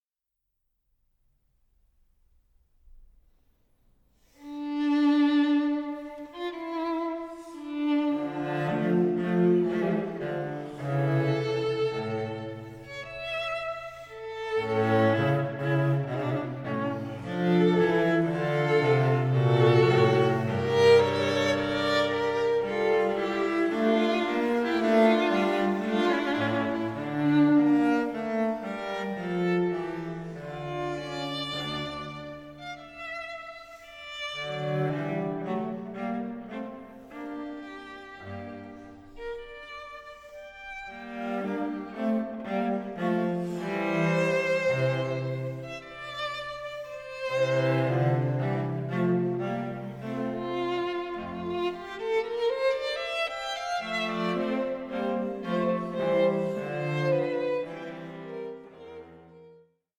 that led the composer to write his music for string trio.